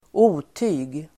Ladda ner uttalet
otyg substantiv, rubbish , mischief , nuisance Uttal: [²'o:ty:g] Böjningar: otyget Synonymer: bus Definition: något obehagligt el. skadligt Exempel: engångsflaskor är ett otyg (non-returnable bottles are a nuisance)